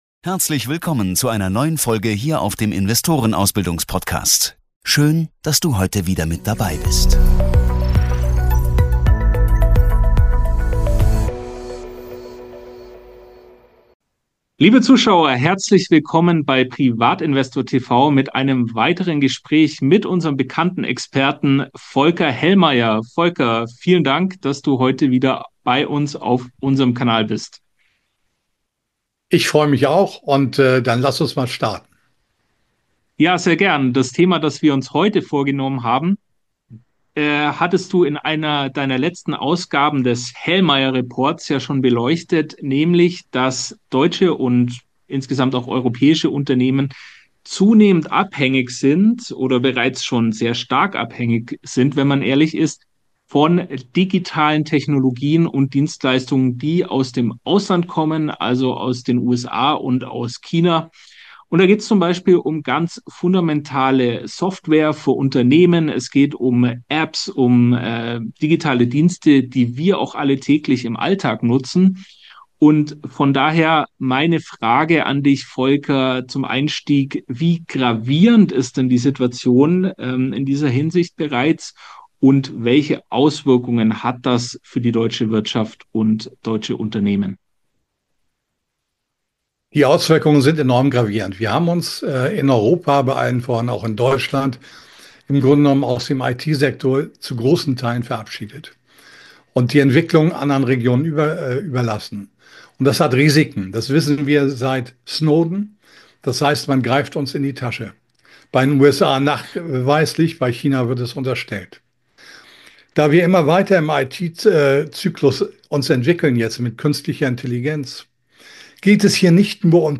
Ein wichtiges Gespräch über digitale Souveränität, verlorene Talente und was jetzt politisch passieren muss.